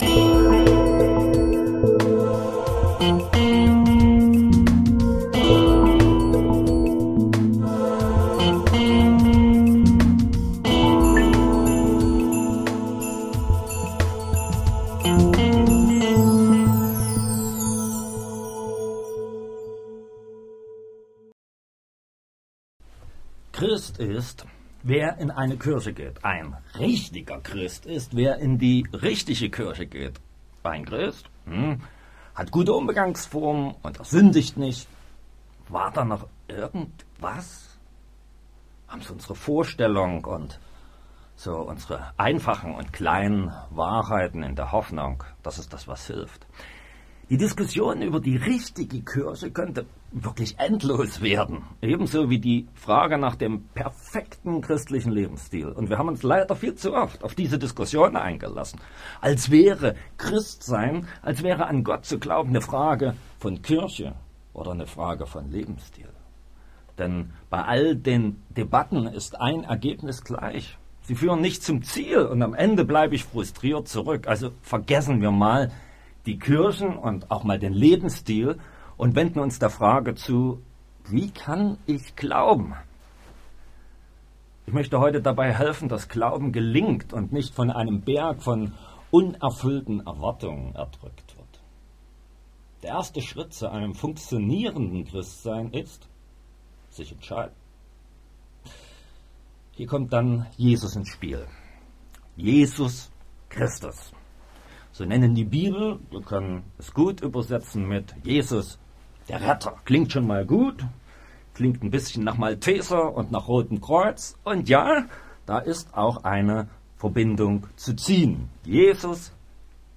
Um den Glauben nicht zu verlieren, gibt es nur eins: Jünger werden! das Thema in 4 Minuten Video-Predigt Audio-Predigt ich entscheide!